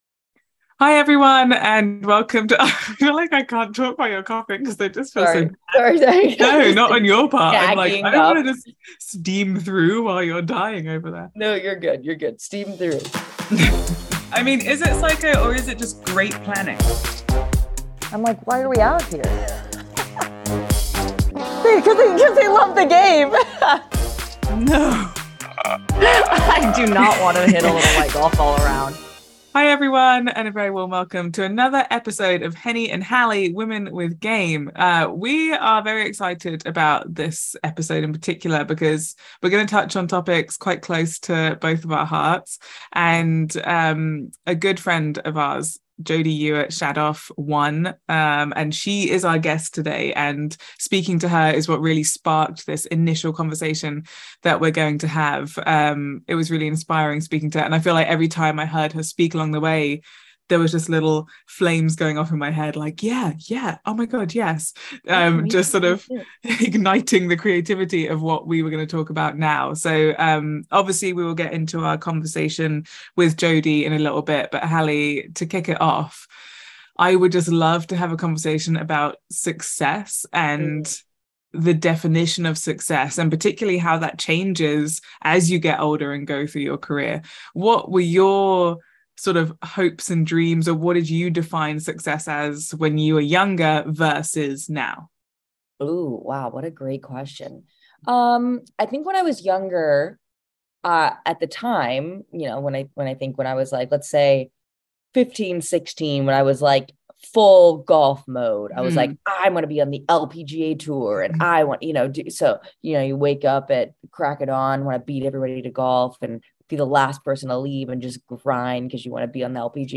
… continue reading 24 episodes # Sports # Golf # Society # Relationship # Lifestyle # Hobbies # Golf Digest # Discussion # Entertainment # Pgatour # PGA